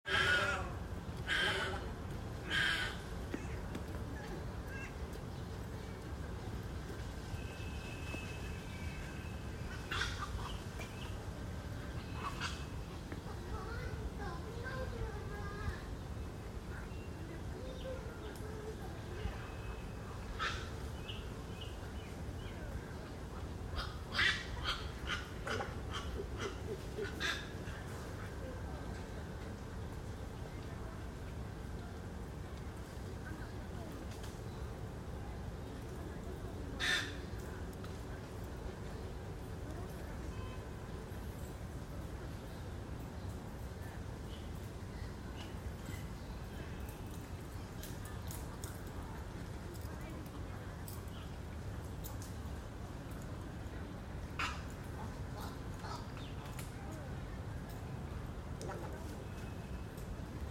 中午在公园录到的环境
鸟巨大声，呕呕叫，吓人的生机